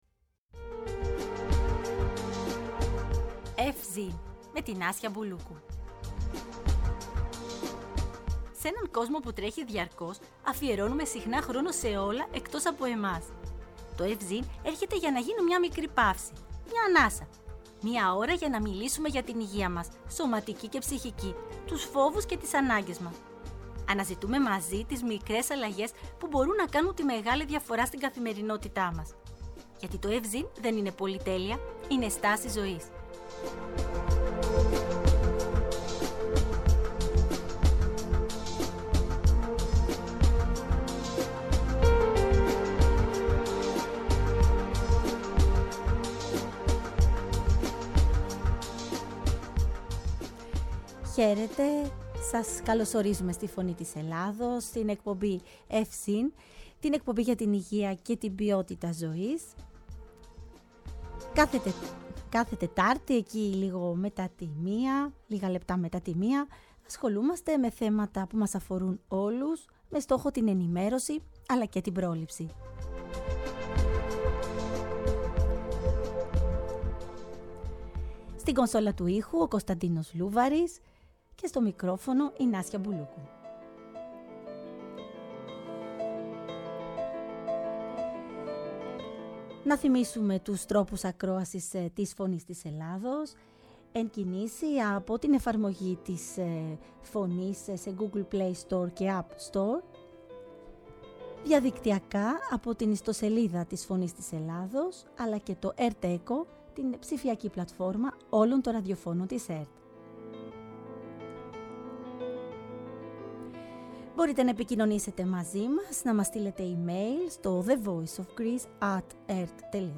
Μια ξεχωριστή συζήτηση για την επιστήμη, την πίστη, την ασθένεια, την ελπίδα και όσα πραγματικά χρειάζεται ο άνθρωπος στις δύσκολες στιγμές.